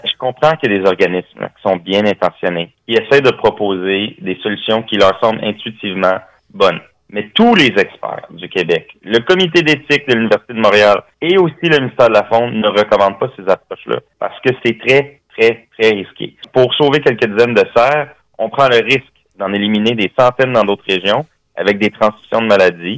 Il a soutenu sur les ondes du FM 103,3 ce jeudi, que le parc souffre beaucoup de la présence des cerfs et qu’il est important d’agir rapidement.